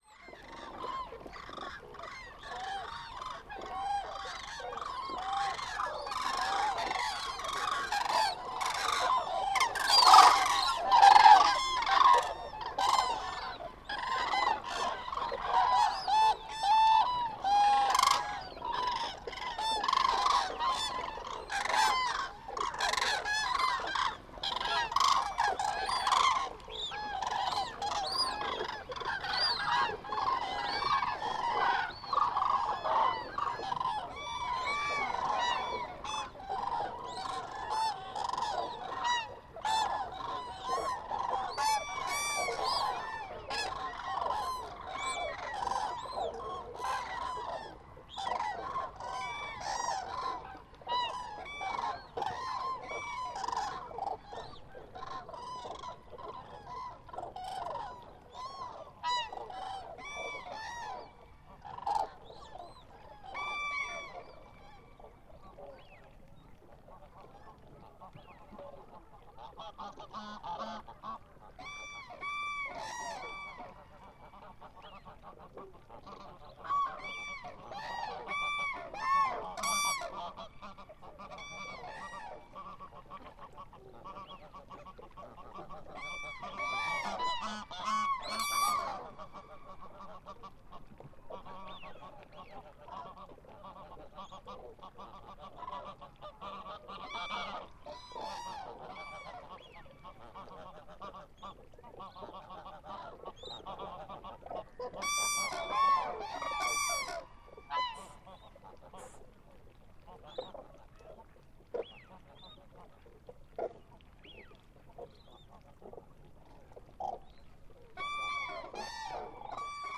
ユニーク且つ小気味良いサウンドが満載。
Ce quatrième opus de la collection « Galets sonores » regroupe une série d’enre-gistrements sur le thème des oiseaux, collectés dans différentes régions de France métropolitaine entre 2011 et 2024.